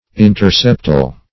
Search Result for " interseptal" : The Collaborative International Dictionary of English v.0.48: Interseptal \In`ter*sep"tal\, a. (Biol.)